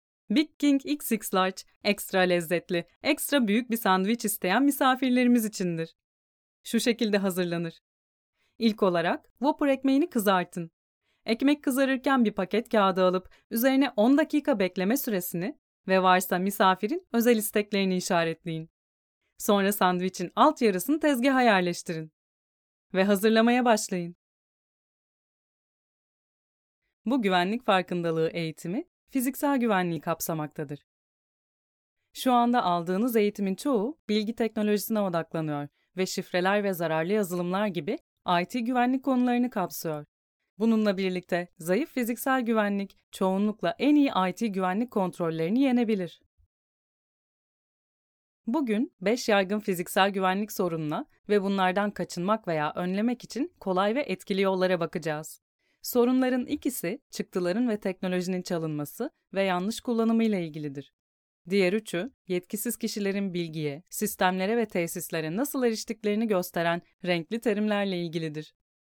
Warm, deep, friendly, natural, feminine, peaceful, Turkish
Sprechprobe: eLearning (Muttersprache):